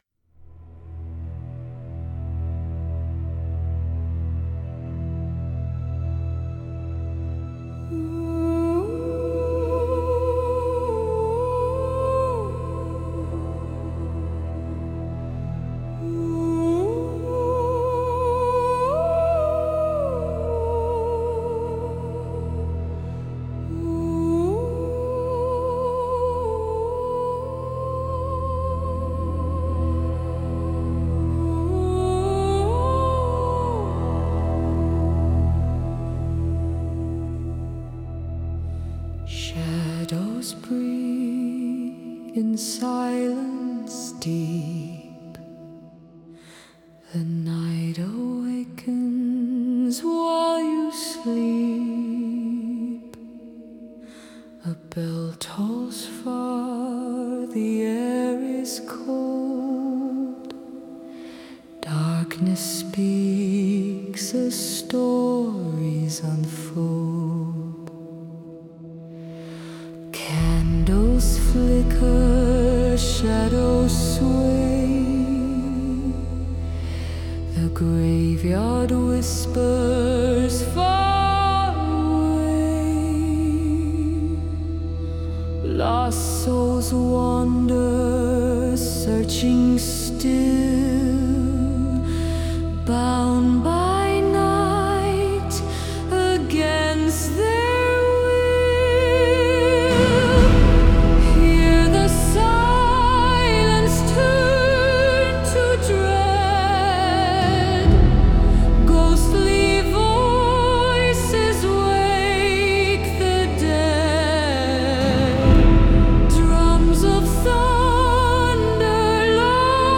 • 4 cinematic horror tracks